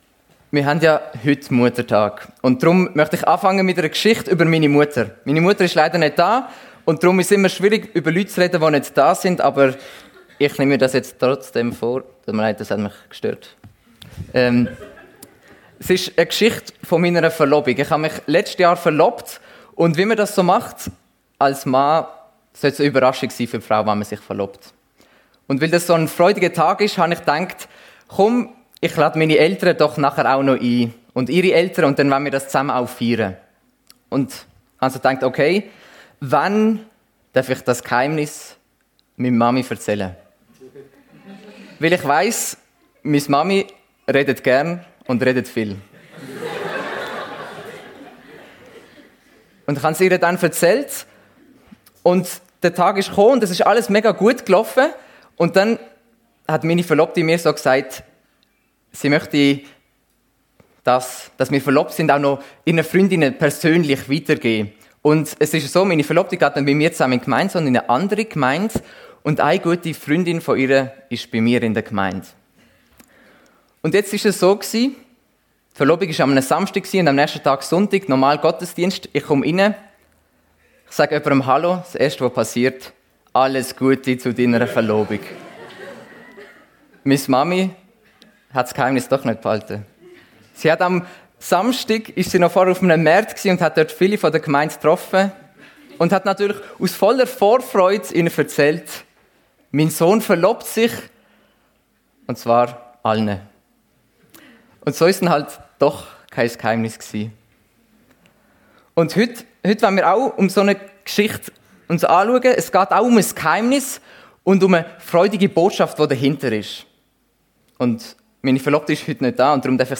Rooted ~ FEG Sumiswald - Predigten Podcast